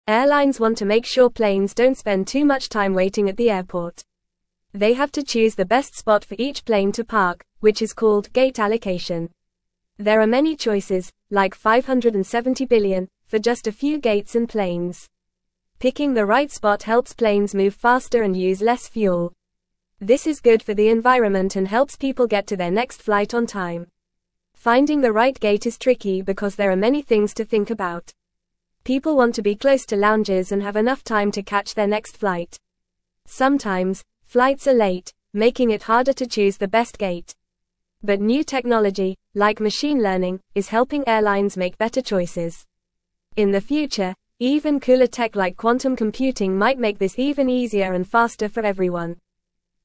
Fast
English-Newsroom-Beginner-FAST-Reading-Choosing-the-Best-Gate-for-Airplanes-at-Airports.mp3